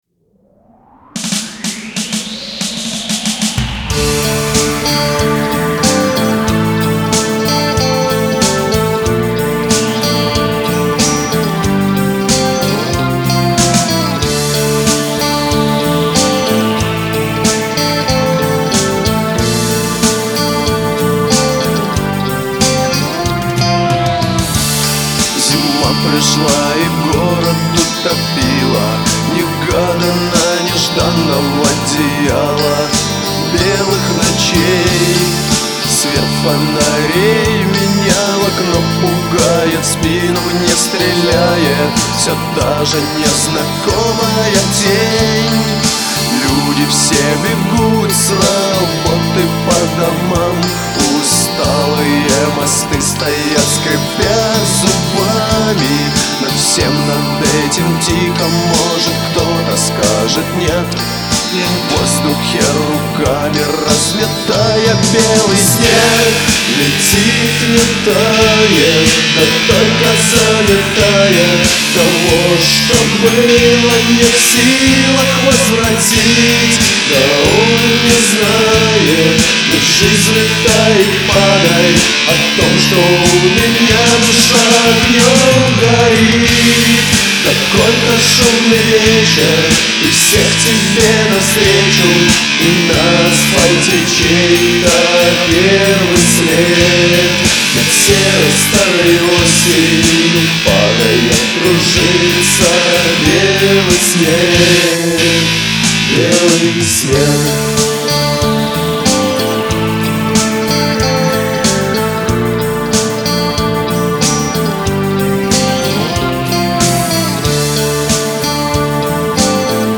Изначально группа работала в направлении панк, панк-рок.
вокал, гитара
бас-гитара, бек-вокал
клавишные
ударные